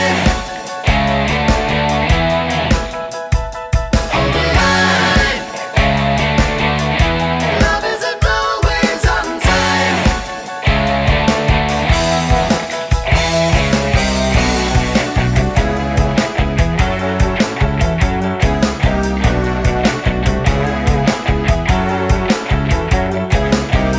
Two Semitones Down Soft Rock 3:50 Buy £1.50